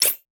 Power Up Select.wav